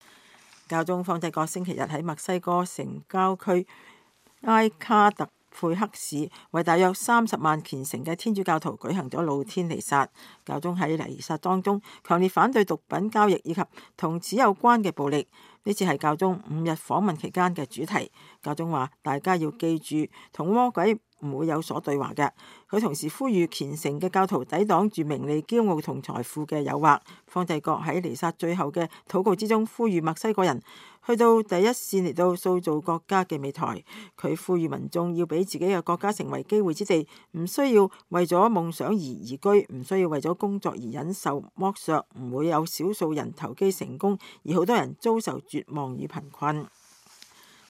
教宗方濟各星期天在墨西哥城郊區埃卡特佩克市為大約30萬虔誠的天主教徒舉行露天彌撒。教宗在彌撒中強烈反對毒品交易和與此有關的暴力。